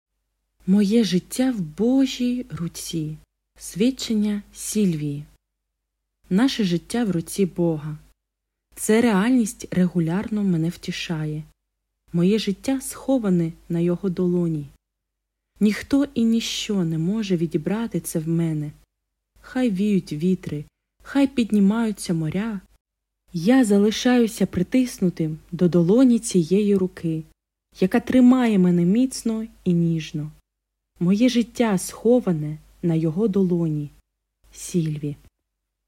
Аудіо, Свідчення